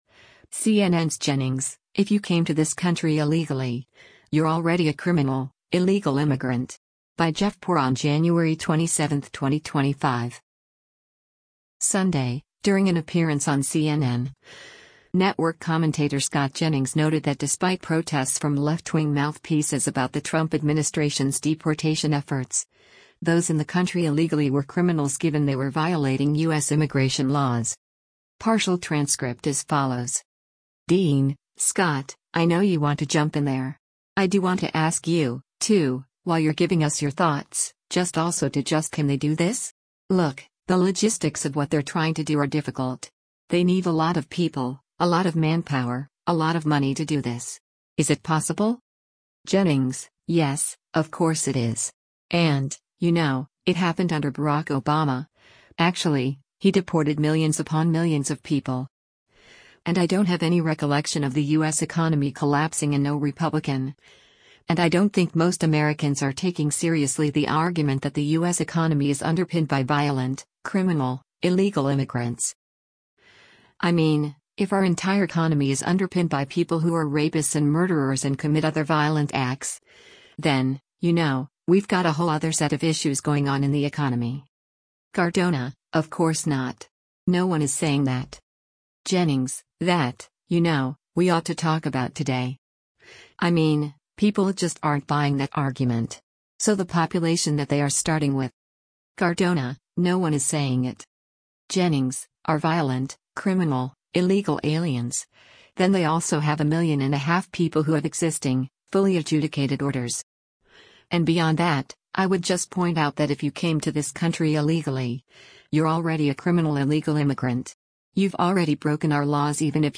Sunday, during an appearance on CNN, network commentator Scott Jennings noted that despite protests from left-wing mouthpieces about the Trump administration’s deportation efforts, those in the country illegally were “criminals” given they were violating U.S. immigration laws.